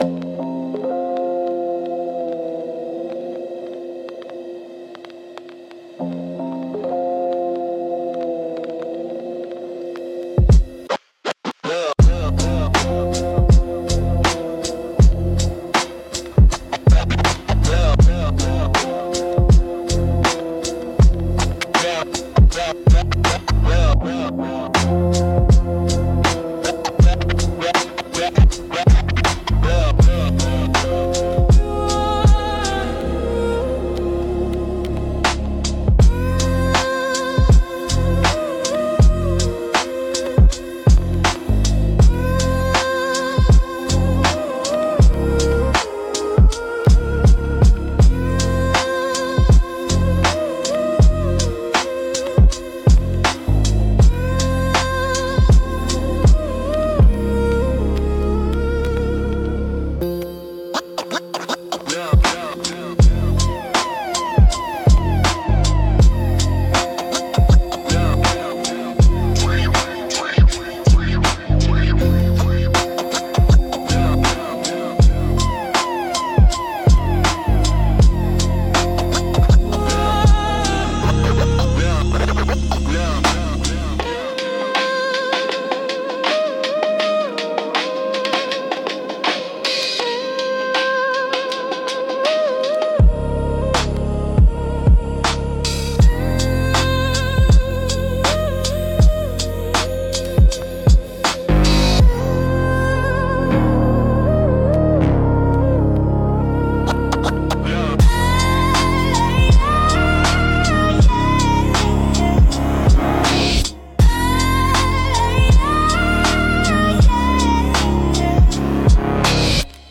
Instrumental - Breathing in the Glitch